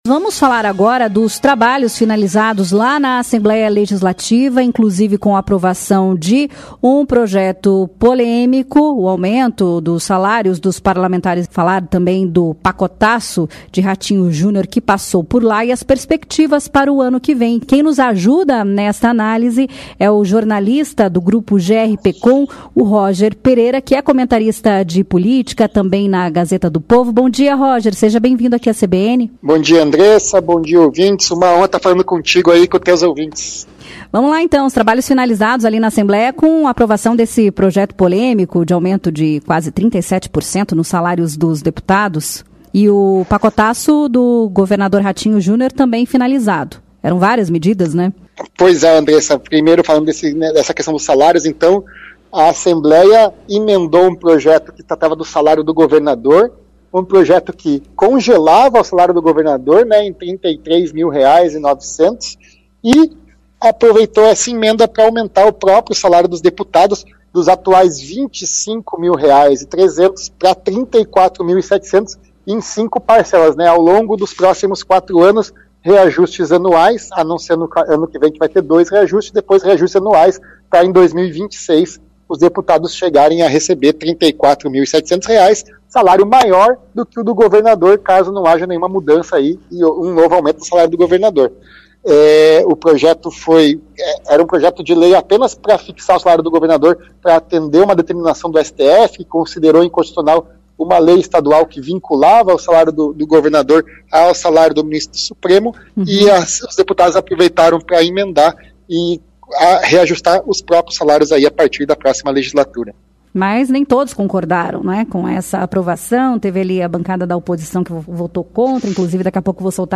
Ouça comentário sobre o encerramento dos trabalhos na Assembleia Legislativa com aprovação de aumento de salário dos deputados e do pacotaço de Ratinho Junior